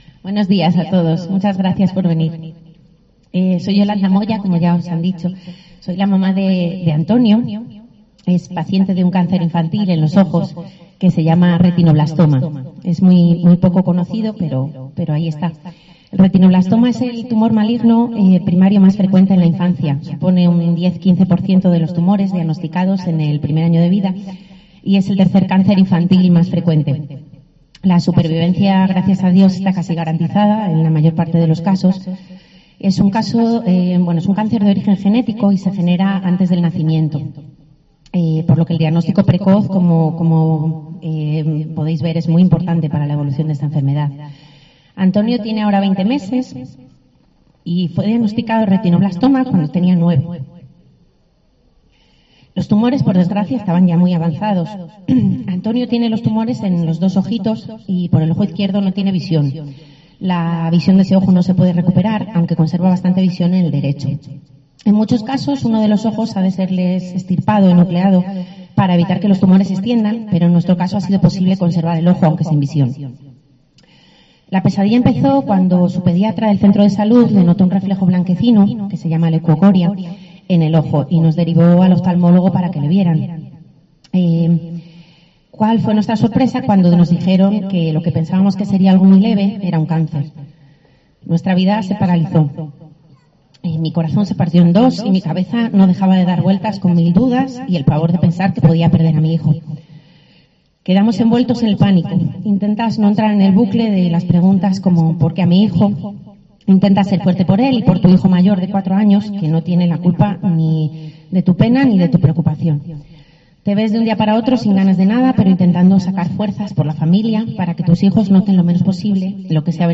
Intervención completa
en el Día Internacional del Cáncer Infantil